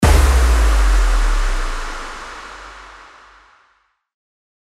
FX-781-IMPACT
FX-781-IMPACT.mp3